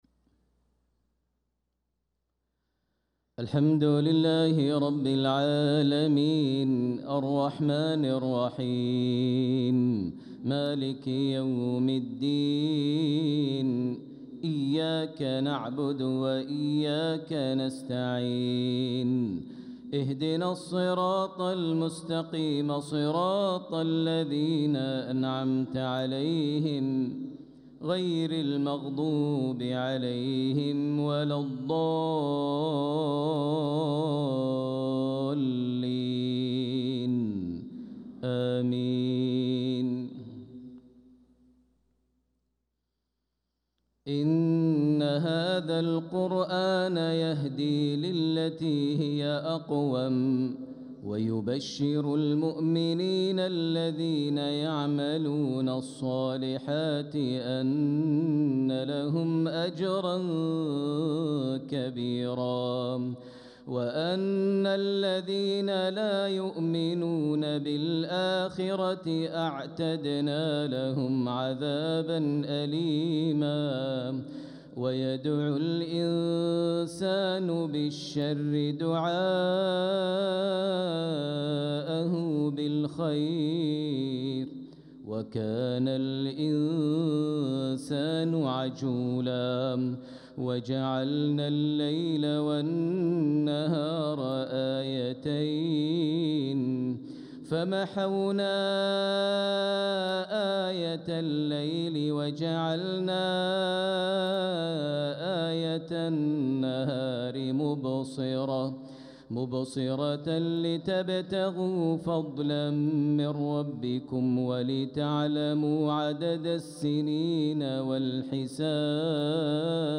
صلاة المغرب للقارئ ماهر المعيقلي 15 صفر 1446 هـ
تِلَاوَات الْحَرَمَيْن .